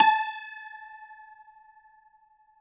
53q-pno17-A3.wav